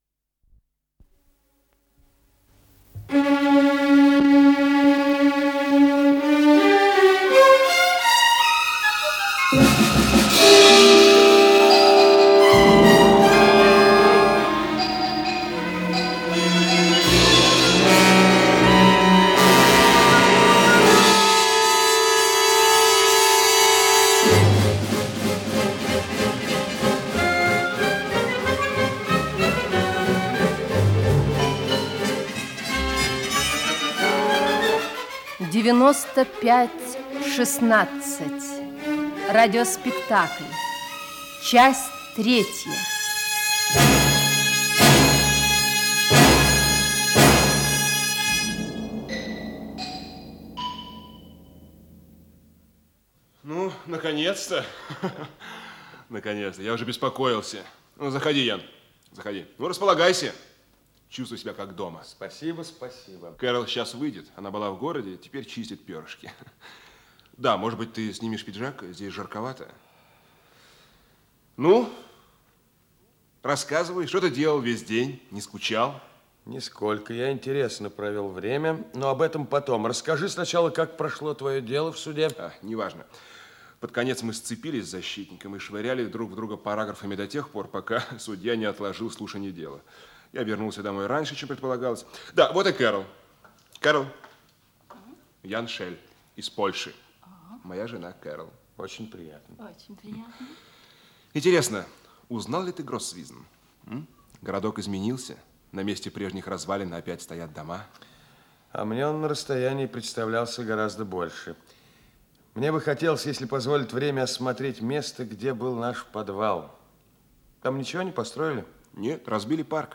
Исполнитель: Артисты московских театров
Радиопостановка в 12-ти частях